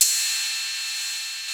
CYM XCHEEZ09.wav